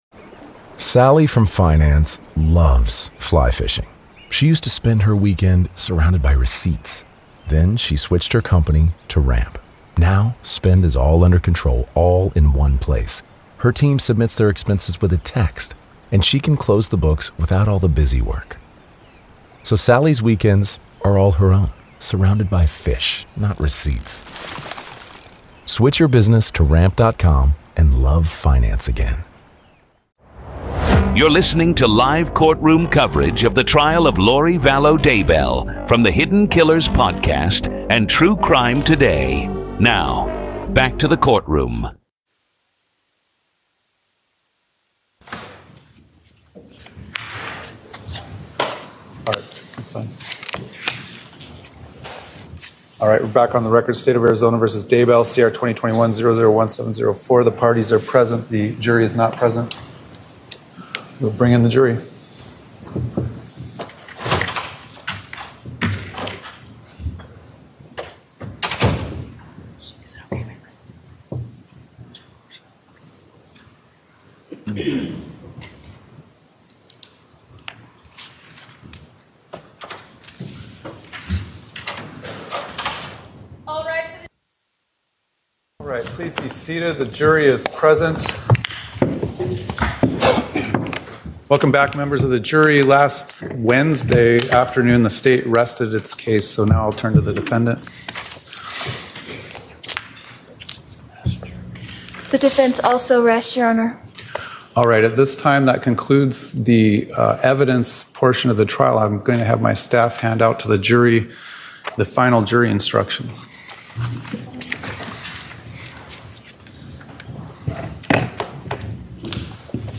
In this episode, you’ll hear the raw courtroom audio from closing arguments in Lori Vallow Daybell’s murder trial—starting with the prosecution’s detailed breakdown of a calculated plan fueled by money, religious delusion, and conspiracy. You’ll also hear Lori herself deliver her own closing argument, defending her actions in a final attempt to sway the jury.